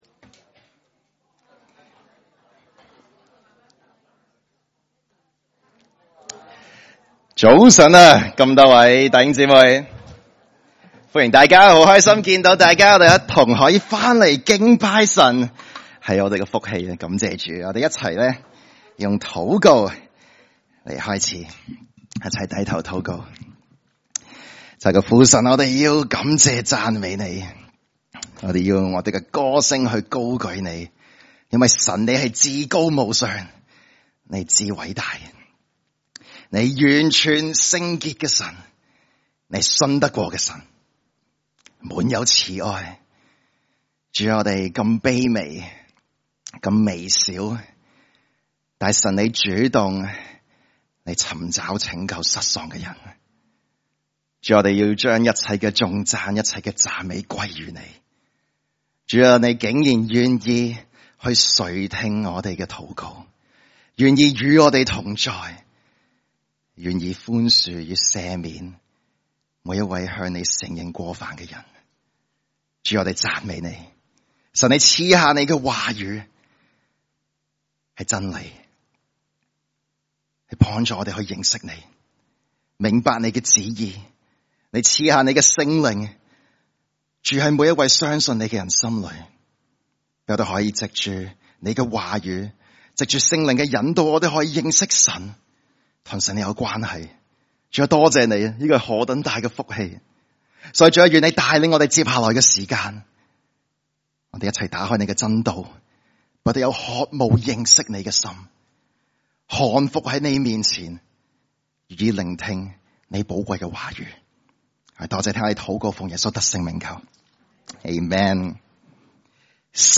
特別講員證道系列
來自講道系列 "解經式講道"